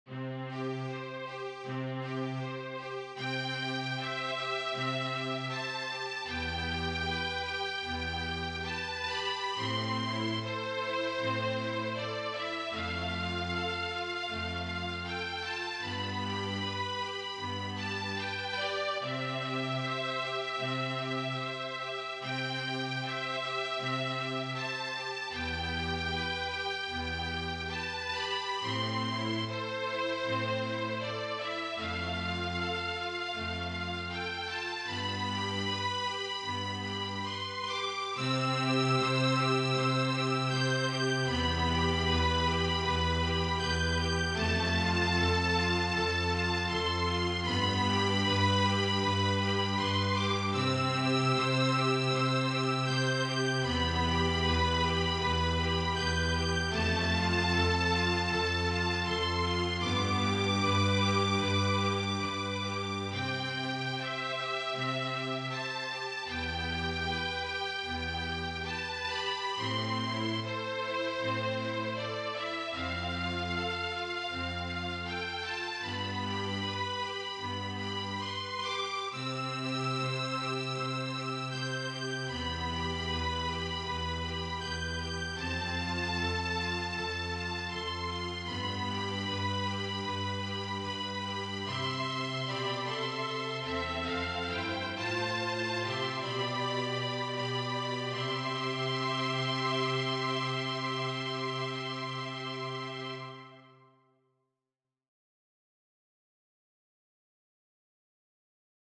a Ritmo di Swing
per sottofondo molto bello a Ritmo di Swing